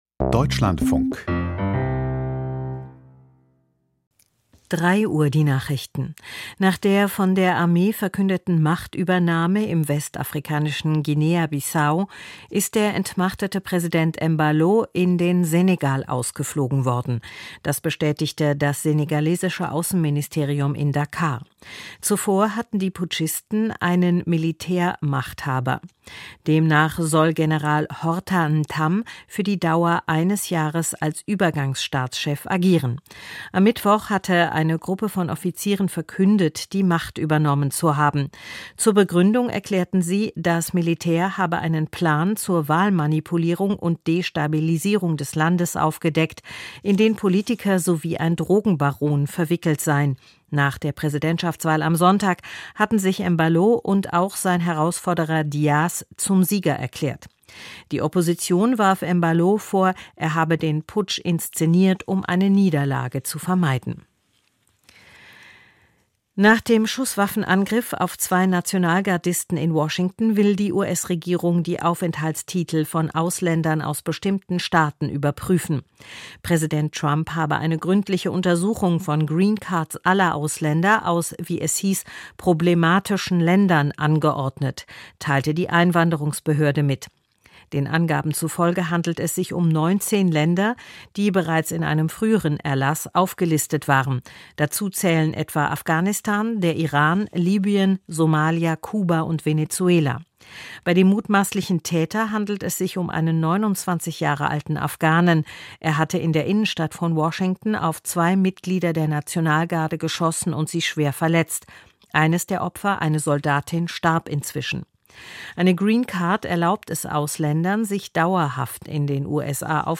Die Nachrichten vom 28.11.2025, 03:00 Uhr